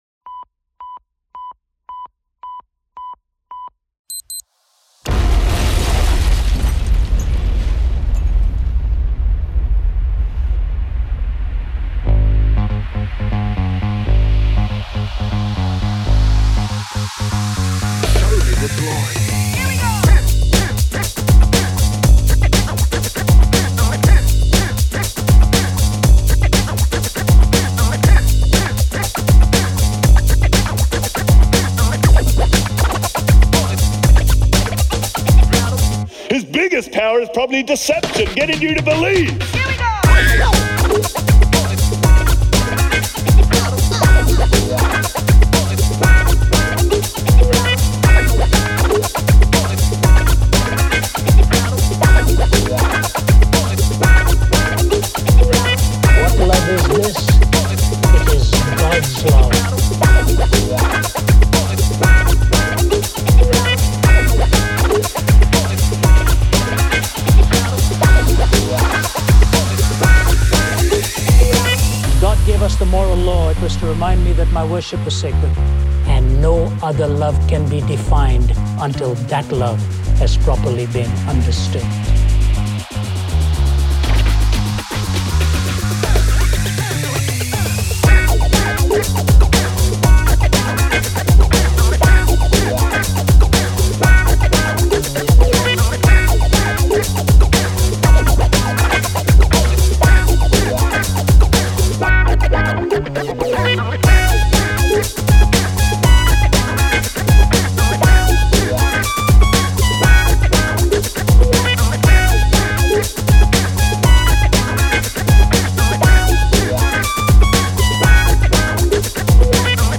Break Beat
Добавил синтов, гитар, эффектов, вокалов, такое вообщем. Нажмите для раскрытия... ударные громче всего в миксе и труба Вложения sDbD2K7J master.mp3 sDbD2K7J master.mp3 6,8 MB · Просмотры: 361